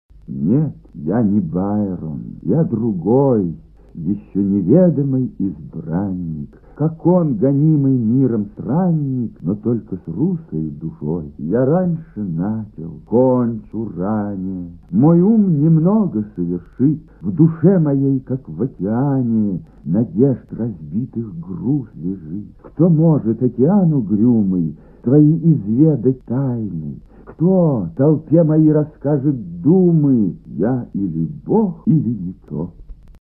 Прослушивание аудиозаписи стихотворения с сайта «Старое радио». Исполнитель Л. Марков.